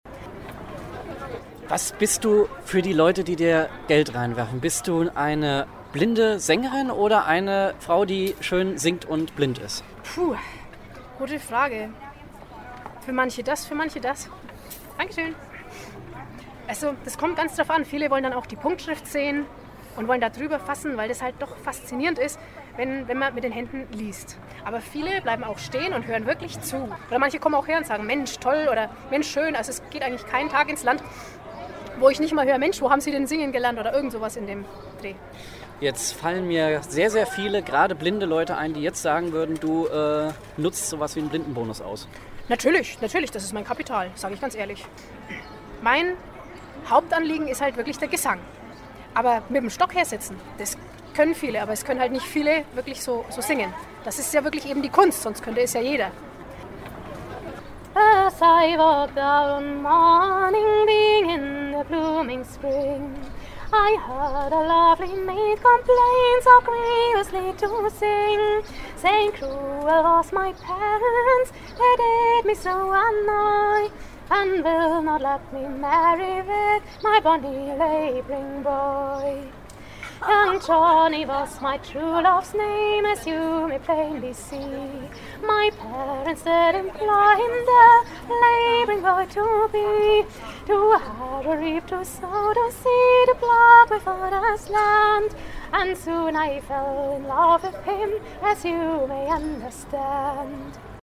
Eine Audio-Reportage über den Sound der Großstadt und die Rhythmen der Natur.
Doch zunächst regnet es Münzen. Klingelnd fallen sie in den Weidenkorb.